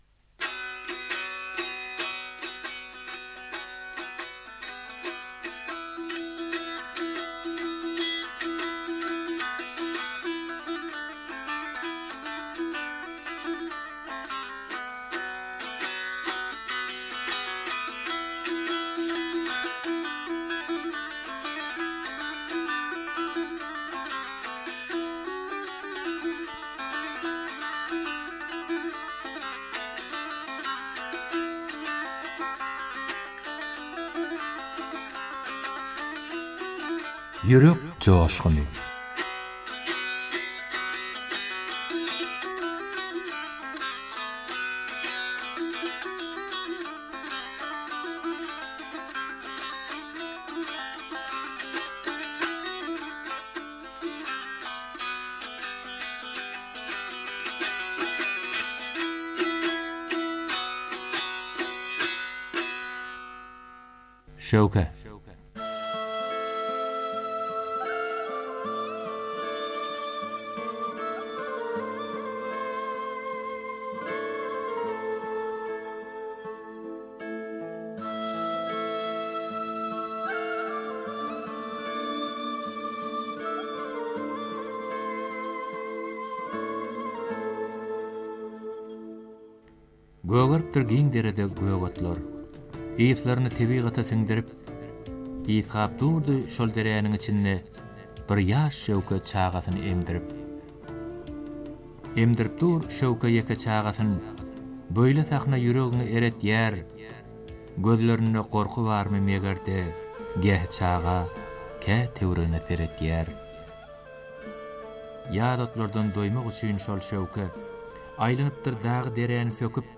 goşgy